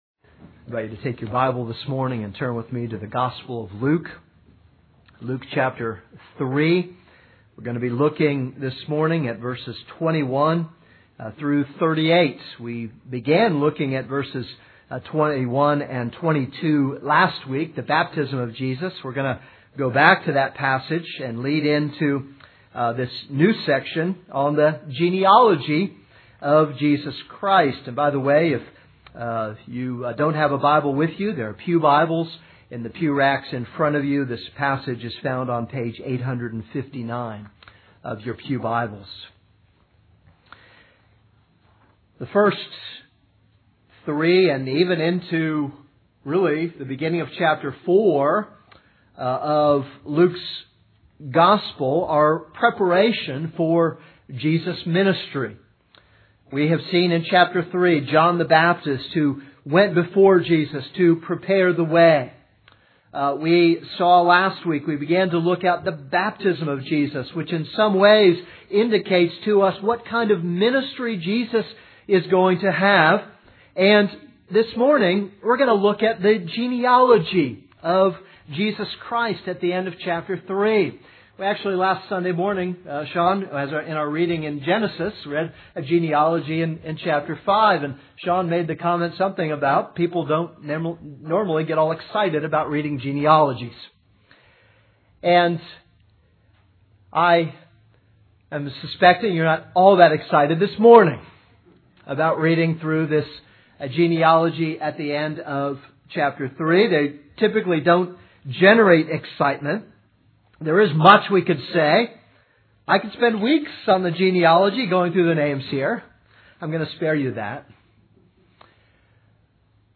This is a sermon on Luke 3:21-38.